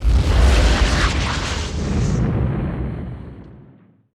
ROCKET SW.wav